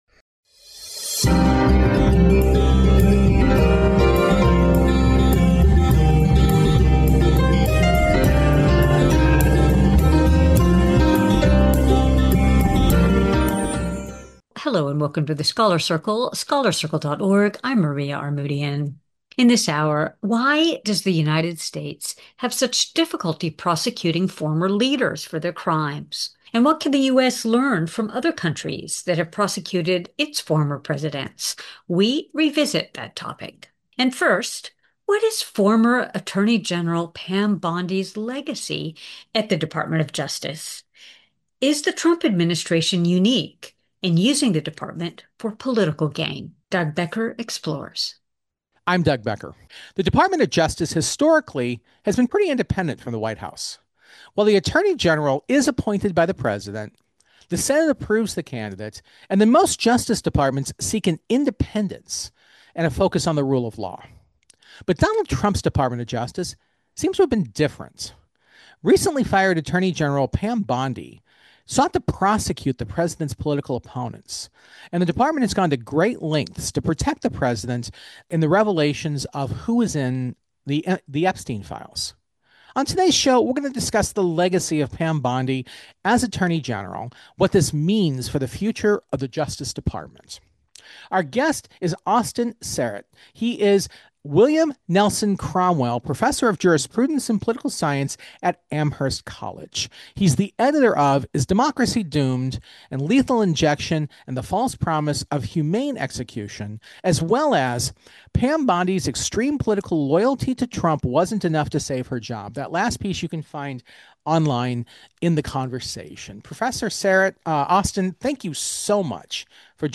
This is a portion of our hour long discussion originally posted in August, 2023.